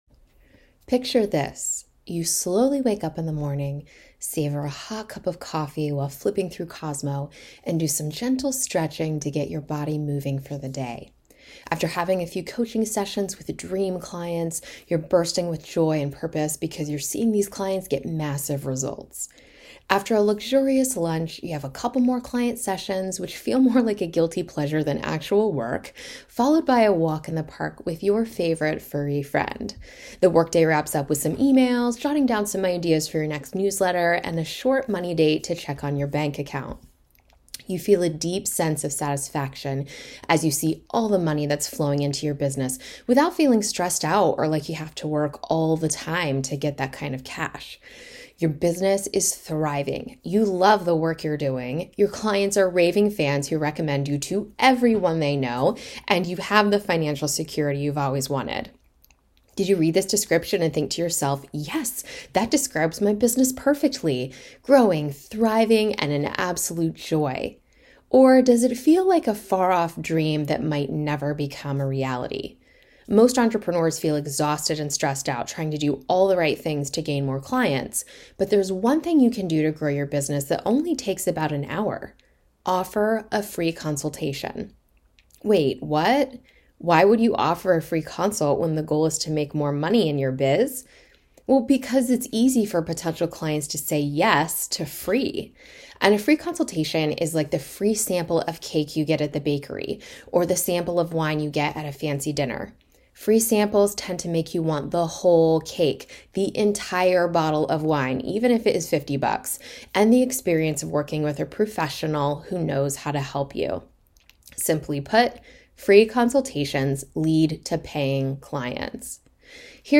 Press play and I’ll read this juicy article to you!